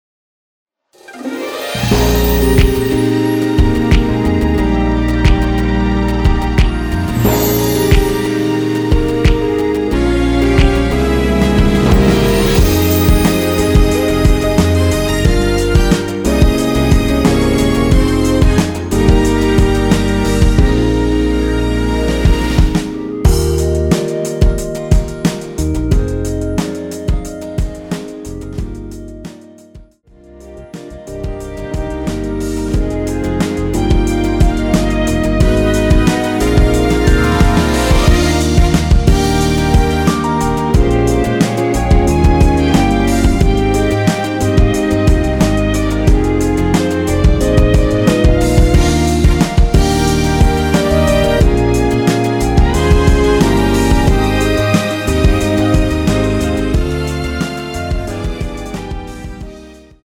원키 (1절앞+후렴)으로 진행되는 멜로디 포함된 MR입니다.(미리듣기 확인)
Eb
앞부분30초, 뒷부분30초씩 편집해서 올려 드리고 있습니다.
중간에 음이 끈어지고 다시 나오는 이유는